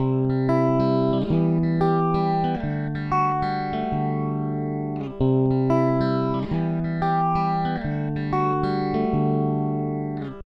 5. Phaser
El phaser puede sonar bastante similar al flanger, pero no es lo mismo.
Como consecuencia, se escucha algo similar al flanger, pero con su propia particularidad:
guitarra-con-phaser_awyts0.ogg